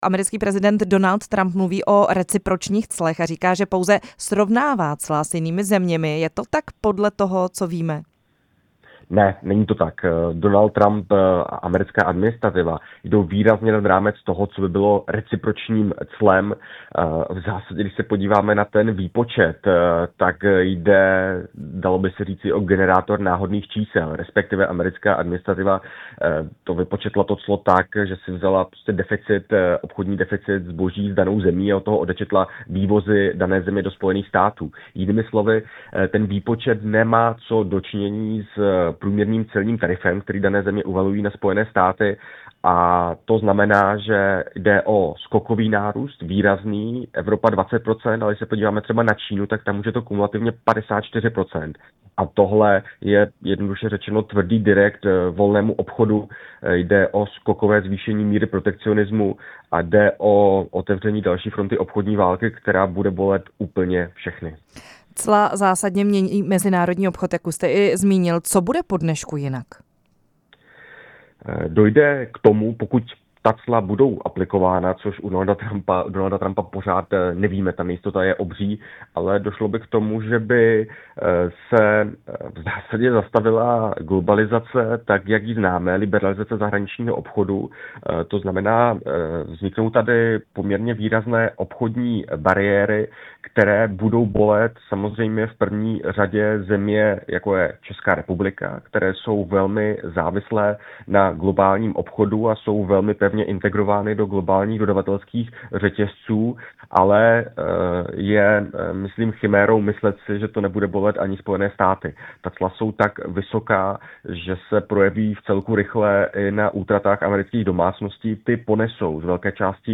Rozhovor s ekonomem